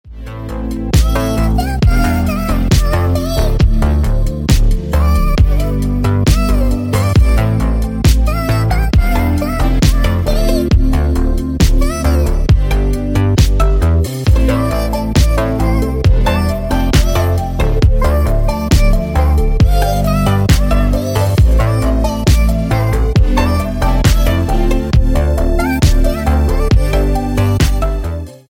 Спокойные И Тихие Рингтоны » # R&B Soul Рингтоны